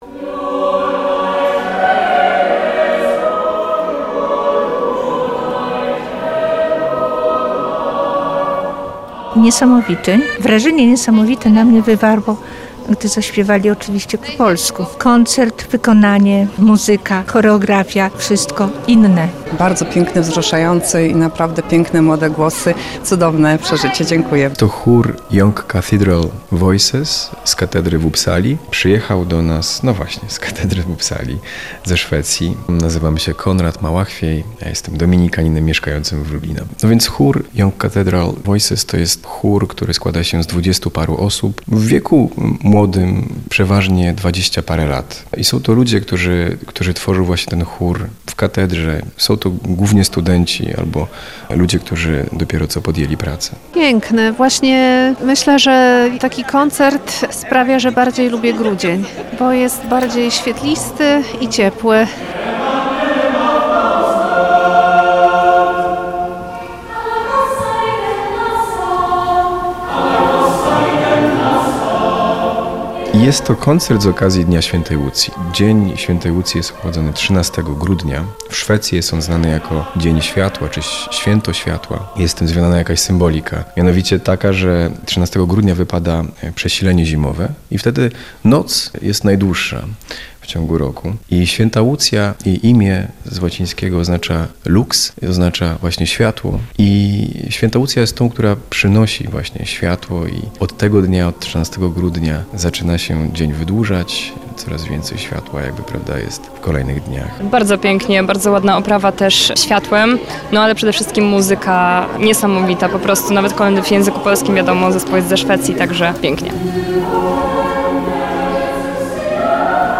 W minionych dniach w Lublinie odbył się koncert z okazji Dnia Świętej Łucji, zorganizowany przez Ambasadę Szwecji. Wystąpił chór Young Cathedral Voices z Katedry w Uppsali, który po raz pierwszy zaprezentował się w naszym mieście.